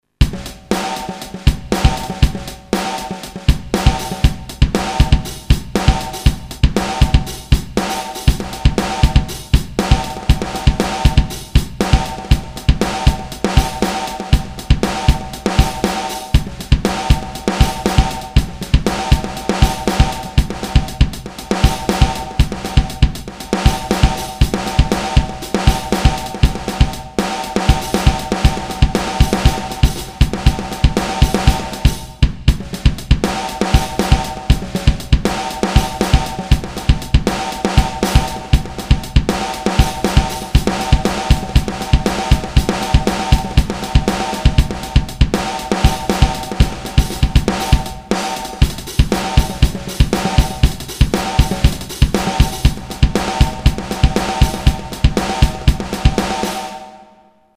Bass & Drums Sound expansion (1995)
Synthesis: PCM rompler